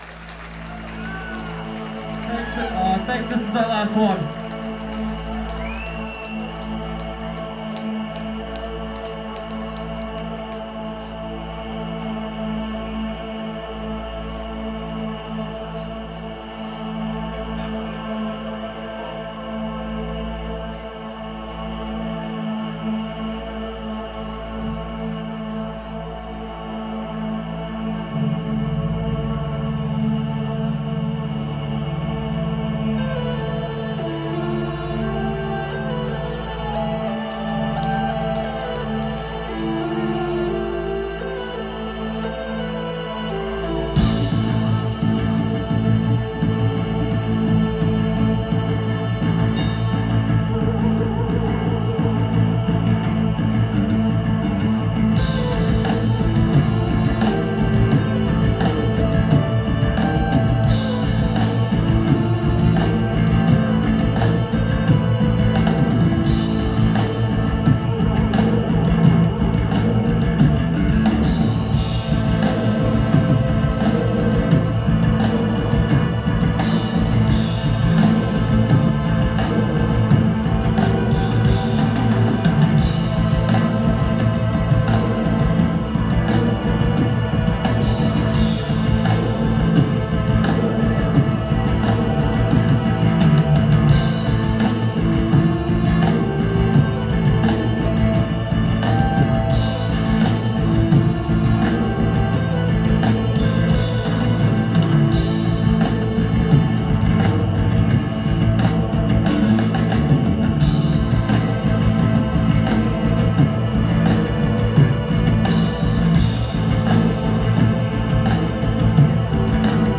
Neumo’s – Seattle, WA